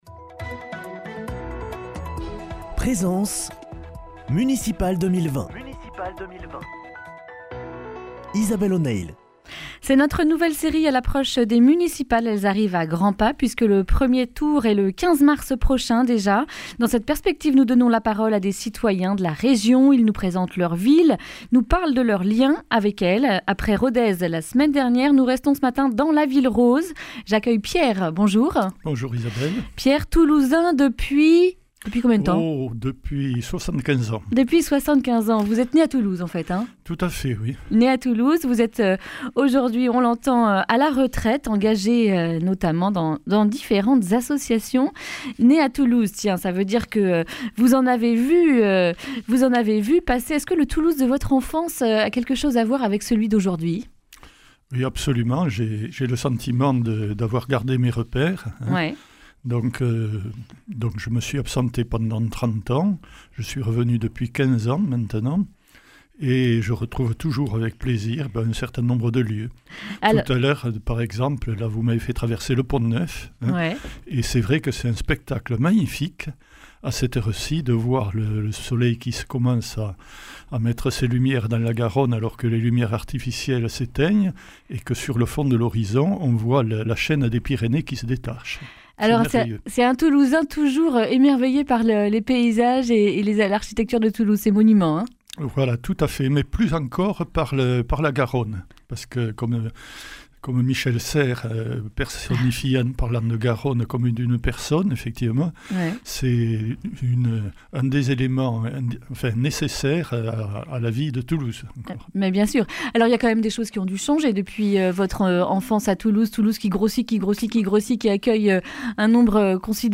jeudi 20 février 2020 Le grand entretien Durée 10 min